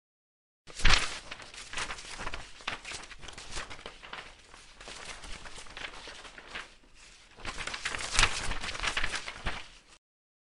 Book opening animation Follow sound effects free download